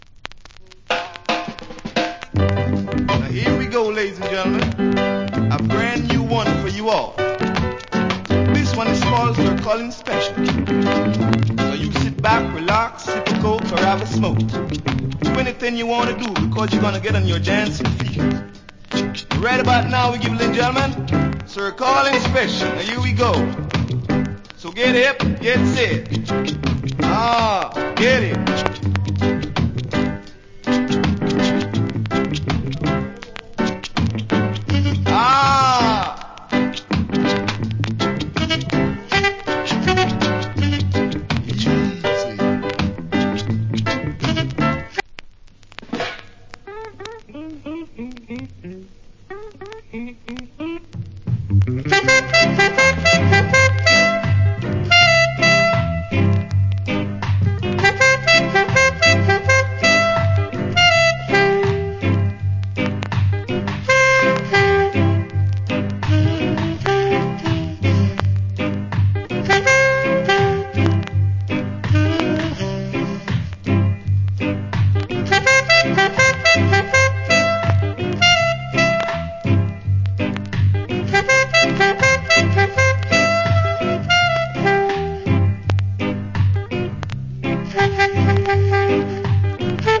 Wicked Inst.